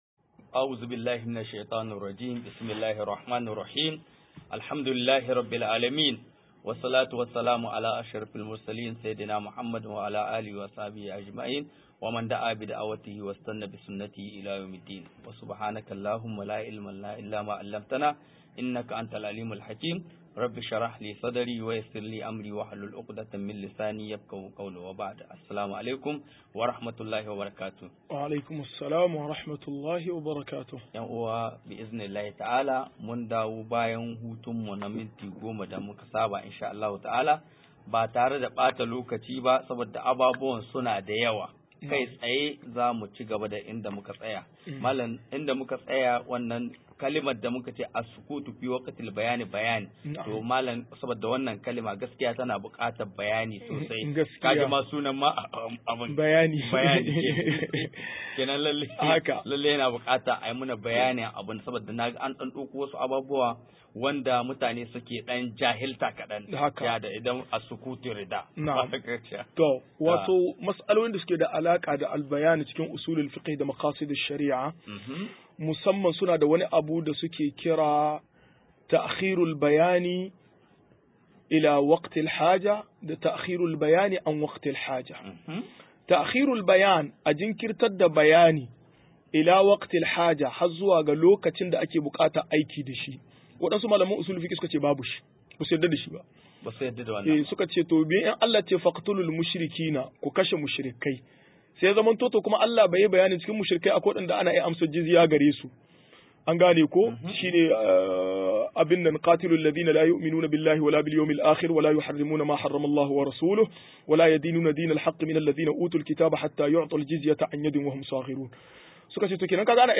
163-Ilimin Makasid Shari a 2 - MUHADARA